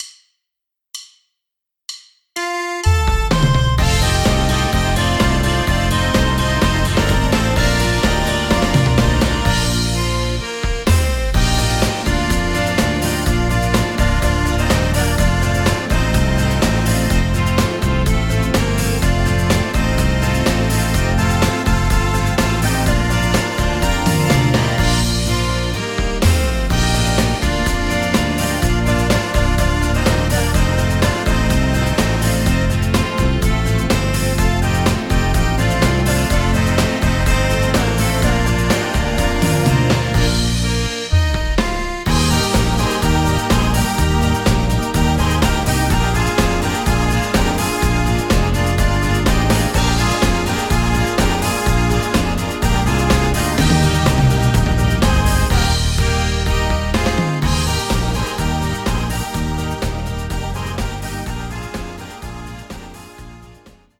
Playback, Karaoke, Instrumental